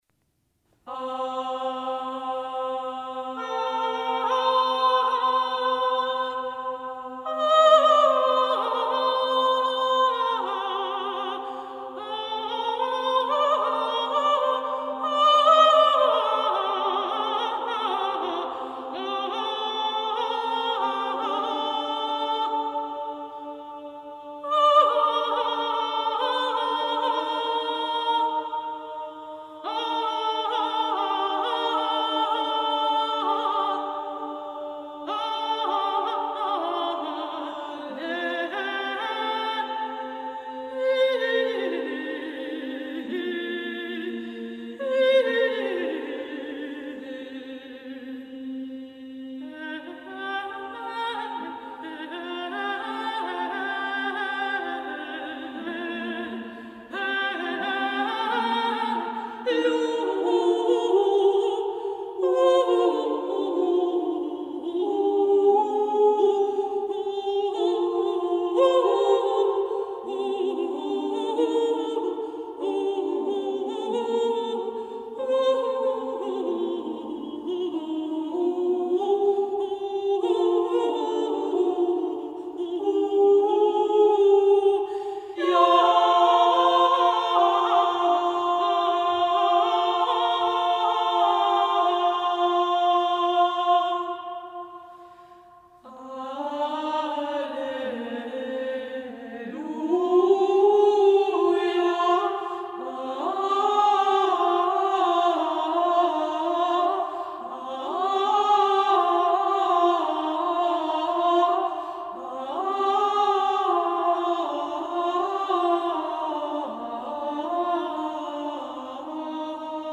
– l'alleluia con aggiunto Homo quo vigeas, da M